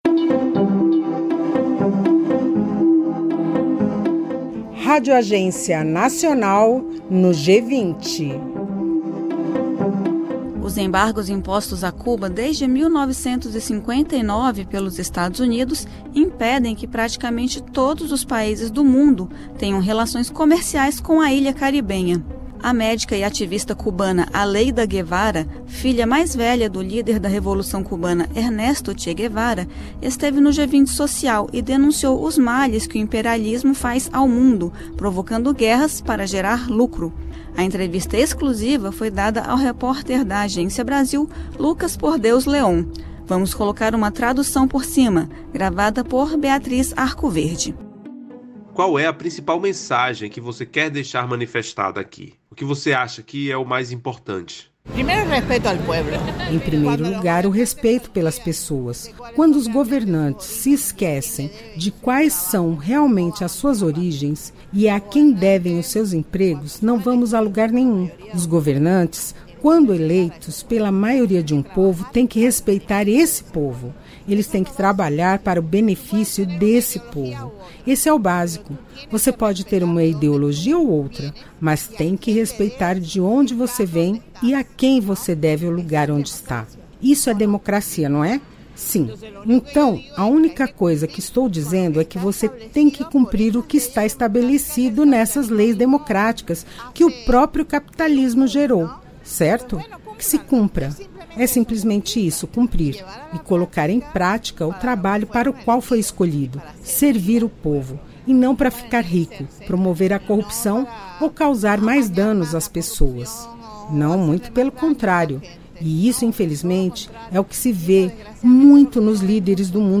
Entrevista.